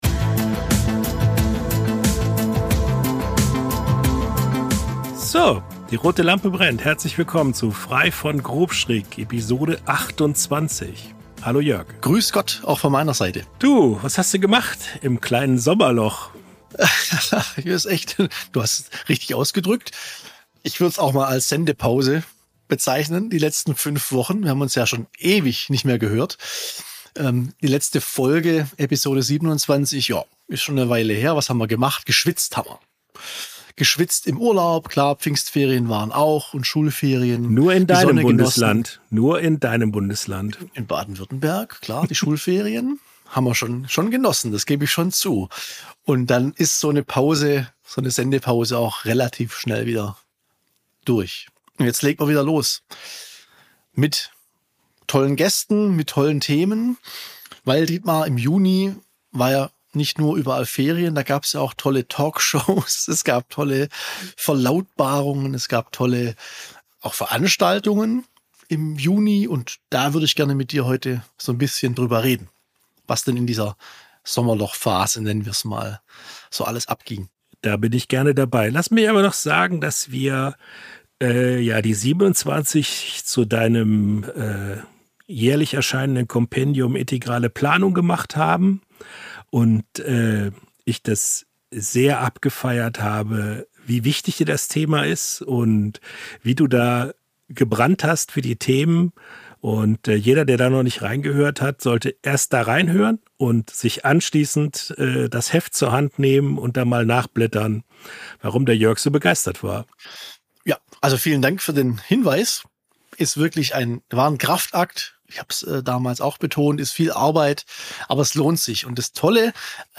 Locker bis launig, meinungsstark und informativ, das ist „Frei von Grobstrick“, der HeizungsJournal-Podcast.